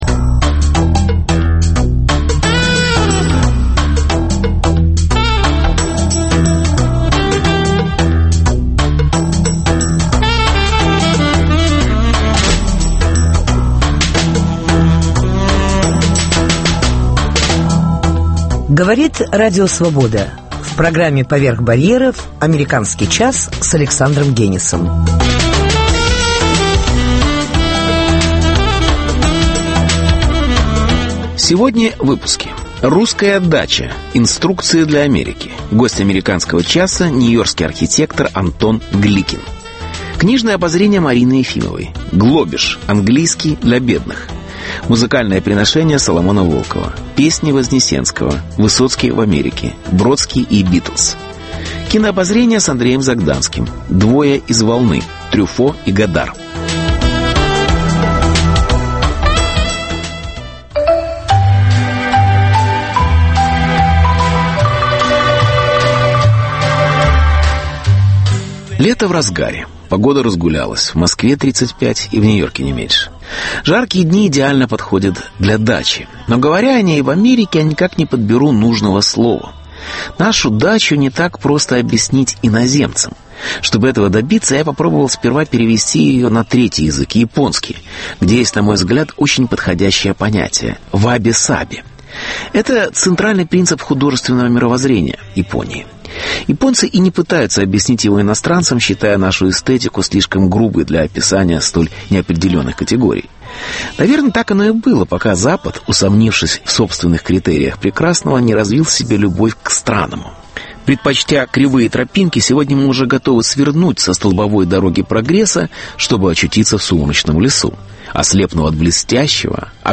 Интервью. Русская дача: инструкции для Америки.